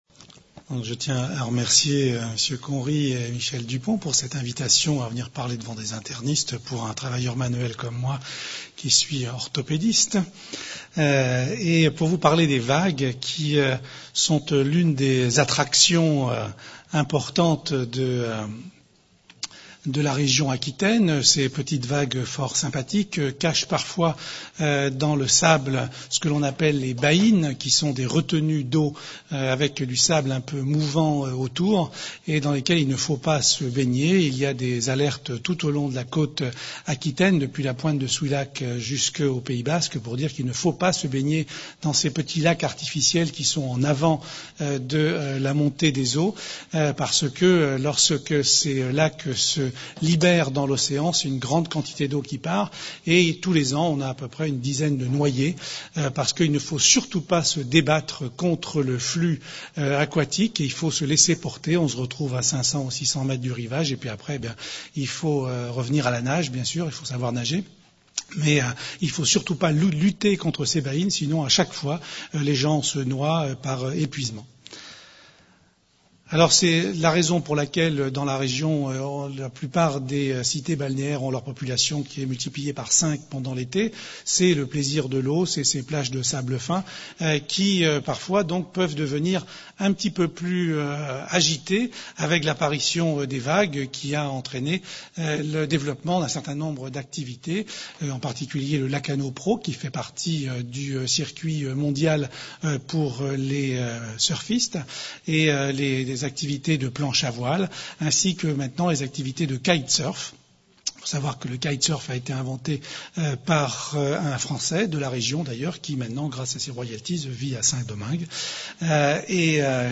La conférence a été donnée le 11 décembre 2008 à l'Université Victor Segalen Bordeaux 2 à l'occasion du 58ème Congrès de la Société Nationale Française de Médécine Interne (S.N.F.M.I.), Session "Pathologies